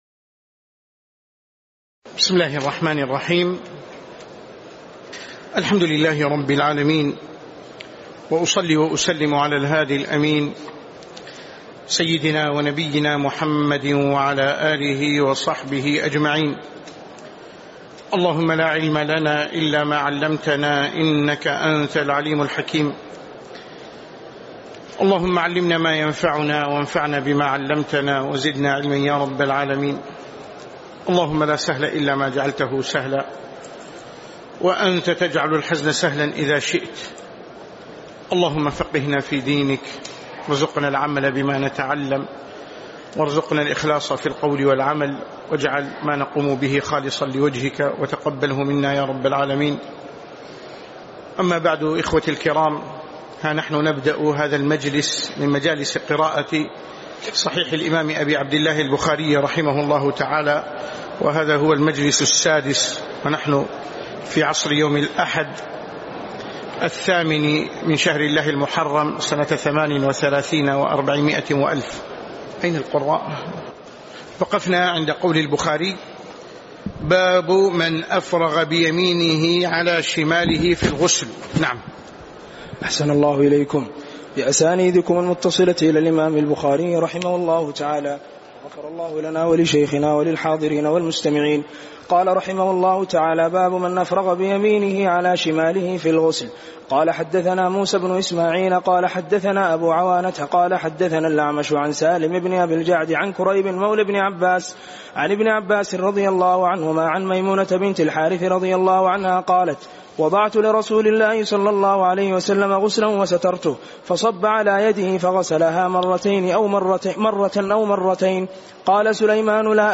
تاريخ النشر ٨ محرم ١٤٣٨ هـ المكان: المسجد النبوي الشيخ